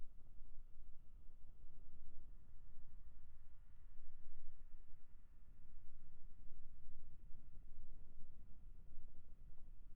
Unlike our other noise cancellation tests, which use reference audio clips, our test setup uses an electrical fan placed 30 cm away from the HATS. We take measurements at three different angles (0°, 90°, and 180°) and upload a recording (where we slowly rotate the HATS from 0° to 180°). Wind noise typically has a flat response below 300 Hz (low mid-range) and tapers off around the same frequency band.
Wind Noise Recording
bose-qc-ultra-headphones-anc-wind-audio.wav